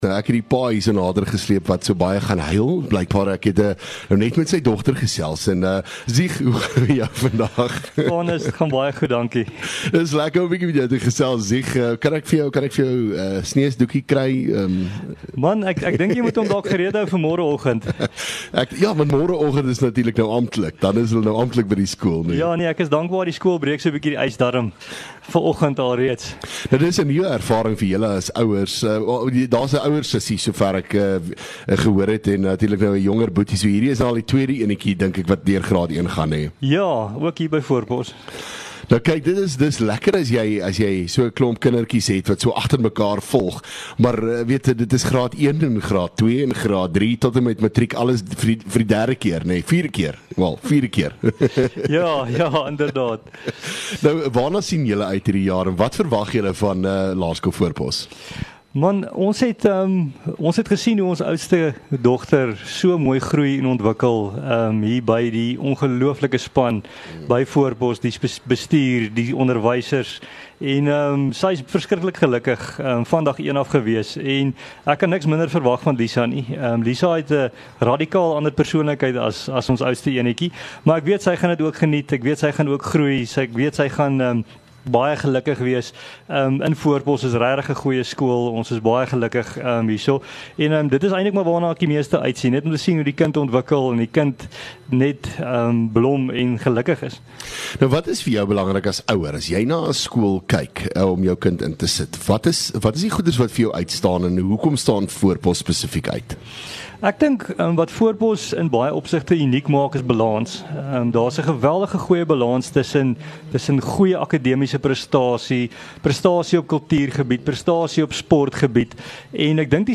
LEKKER FM | Onderhoude